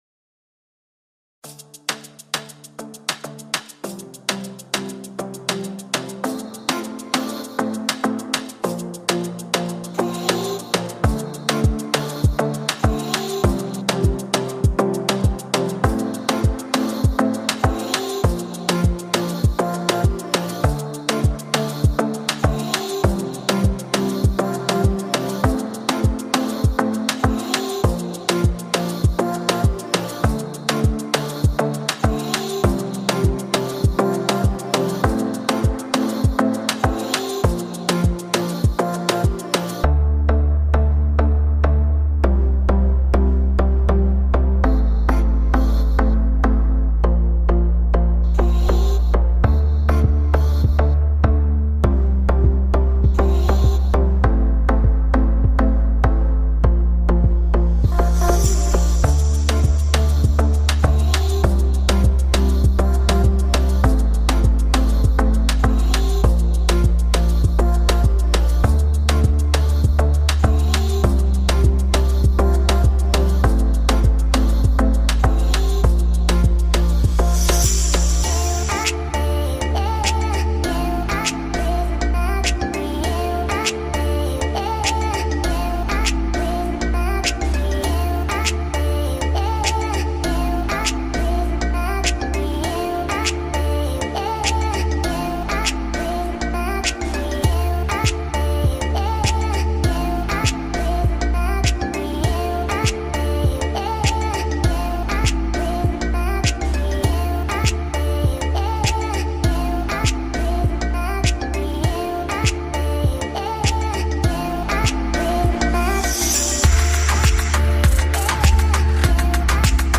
tema dizi müziği, heyecan eğlenceli enerjik fon müzik.